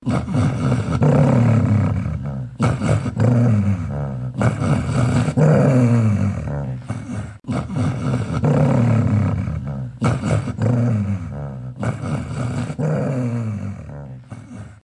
Tiếng con Chó gầm gừ rất tức giận, giận giữ
Thể loại: Tiếng vật nuôi
Description: Tiếng con chó gầm gừ vang lên đầy tức giận, như tiếng rít sâu trong cổ họng, khàn đặc và dữ dội. Âm thanh ấy vừa trầm, vừa kéo dài, nghe như tiếng “grừ... grừ...” rung lên từng hồi, thể hiện cơn thịnh nộ và cảnh giác cao độ. Tiếng gầm pha lẫn hơi thở gấp gáp... đầy cảnh báo...
tieng-con-cho-gam-gu-rat-tuc-gian-gian-giu-www_tiengdong_com.mp3